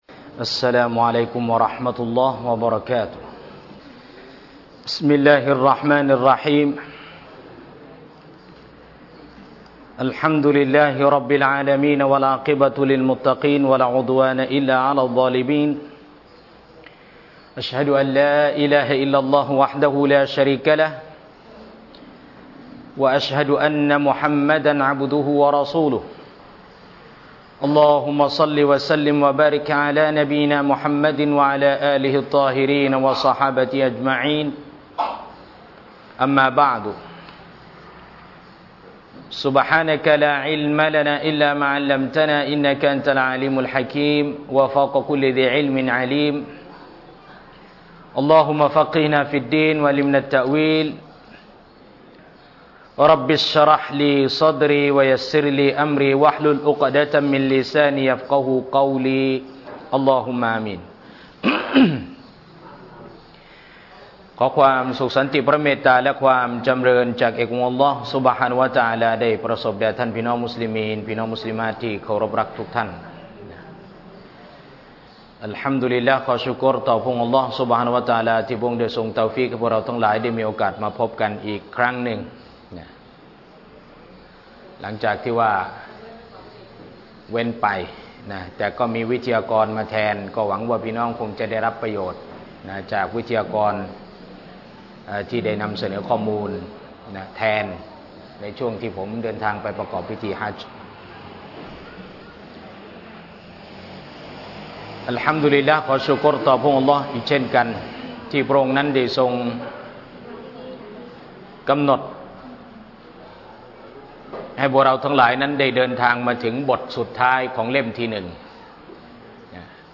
โครงการอบรมจริยธรรมและการศึกษา ณ ศูนย์อบรมจริยธรรมประจำมัสยิด มัสยิดนูรุ้ลอิสลาม (คูคต)